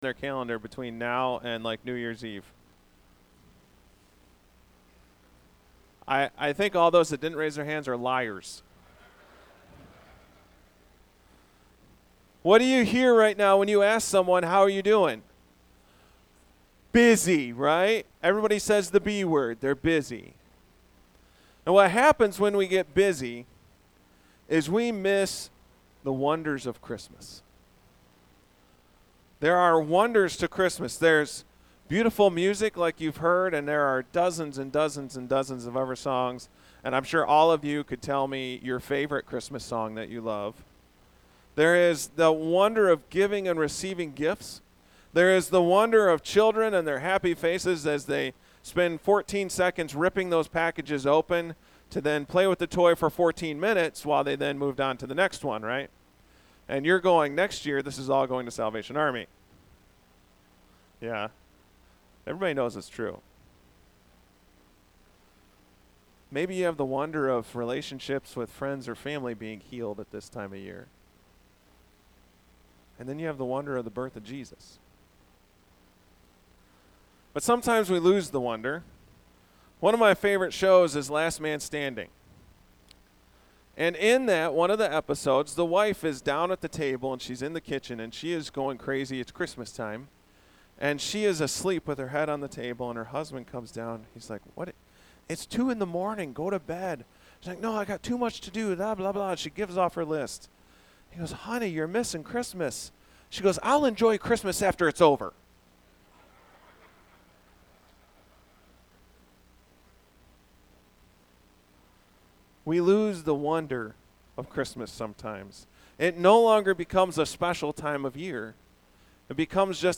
Our annual Christmas program is presented by our Christian Education Department. Each class creates their own presentation for your enjoyment. We certainly are not professionals, but we love the Lord and we hope that shows!